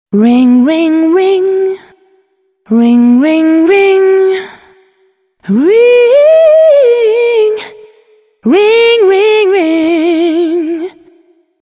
ženský hlas zo spevom 0:03